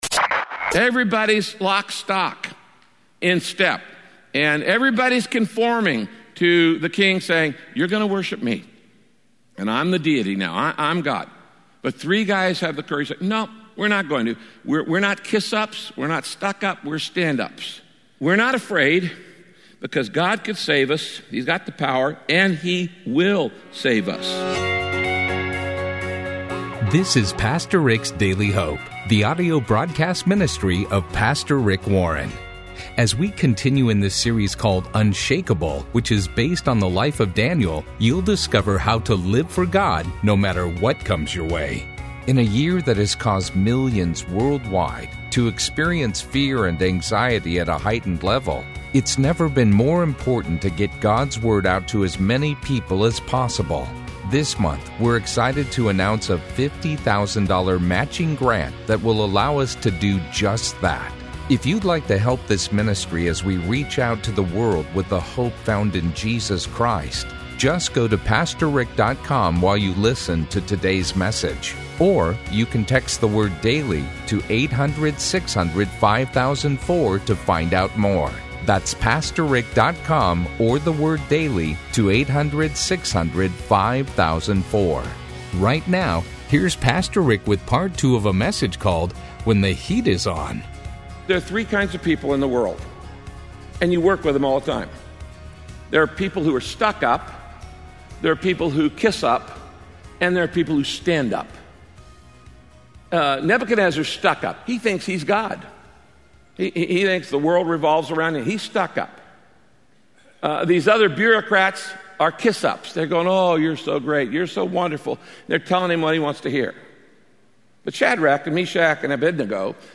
Radio Broadcast When The Heat Is On – Part 2 Teaching from the life of Daniel, Pastor Rick explains how God will give you a new freedom as he walks with you through the fire.